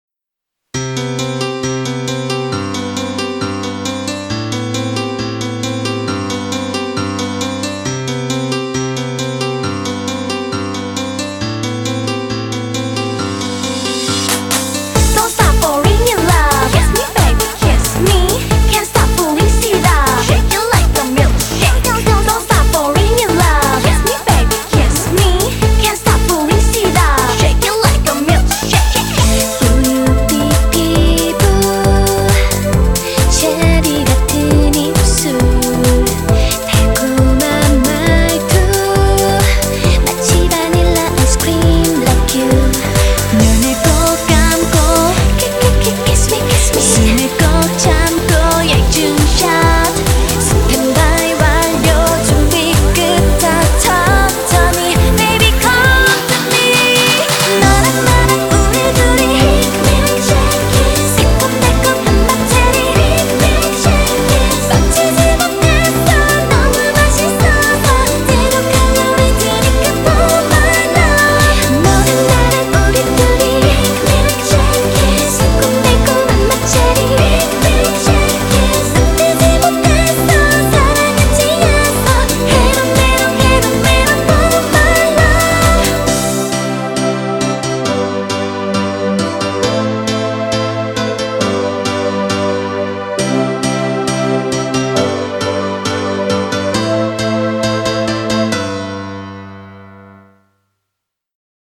BPM135
I thought I would be refreshing to step some pop